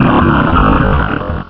pokeemerald / sound / direct_sound_samples / cries / regirock.aif